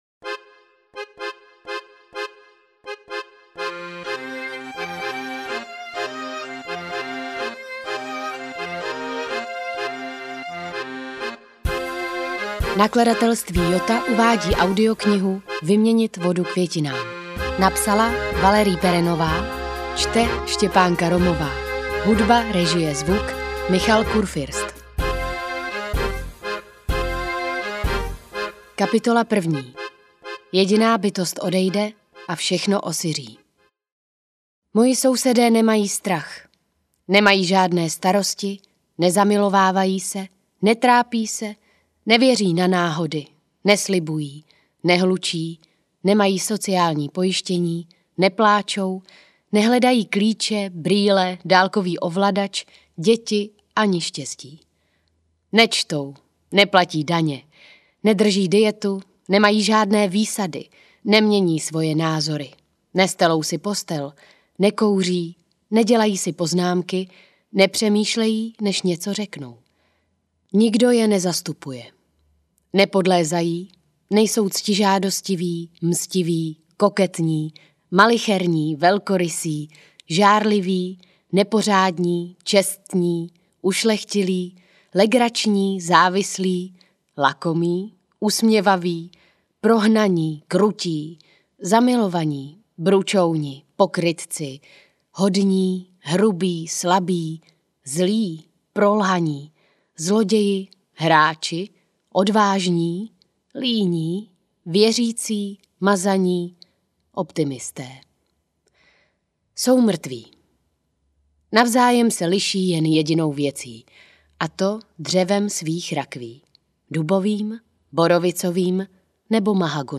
Vyměnit vodu květinám audiokniha
Ukázka z knihy